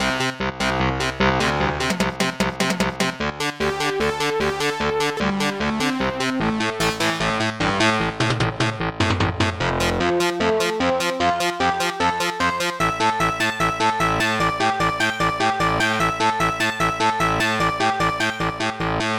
Protracker Module
st-01:bigbass2 st-01:synth2 st-01:snare2 st-01:synth3 st-01:elecguitar